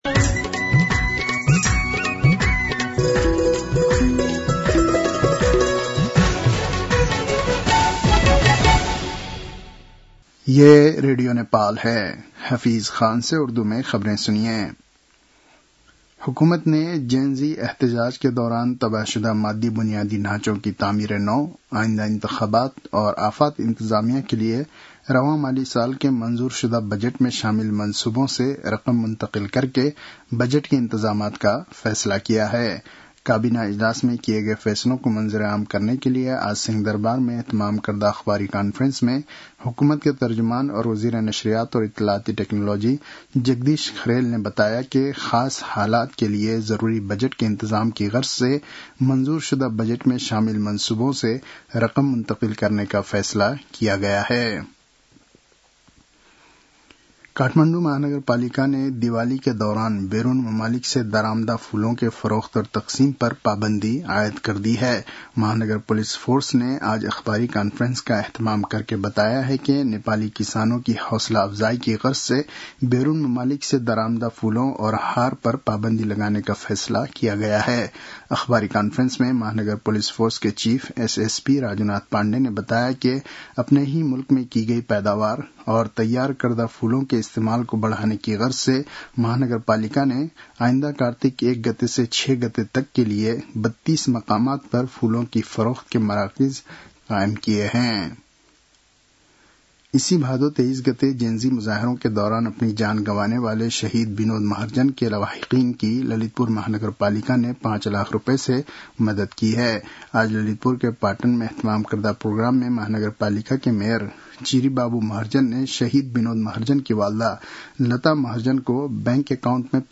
उर्दु भाषामा समाचार : २४ असोज , २०८२